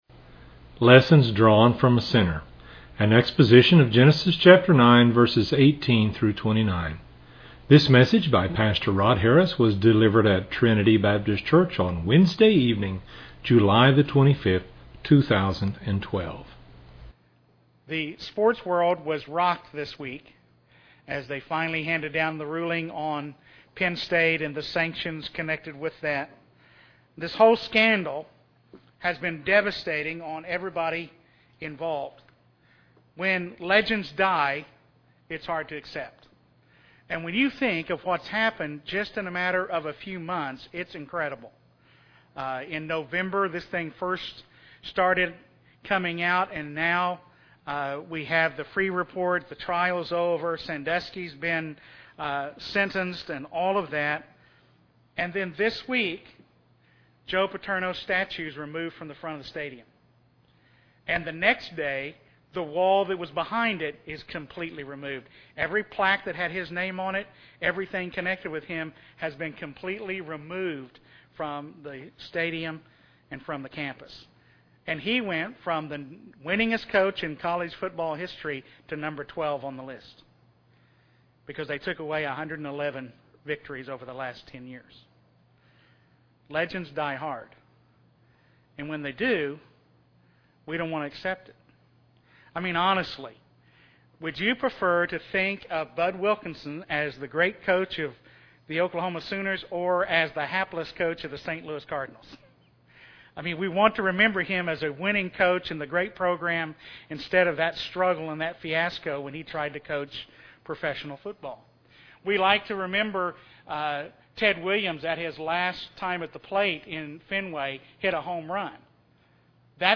at Trinity Baptist Church on Wednesday evening, July 25, 2012.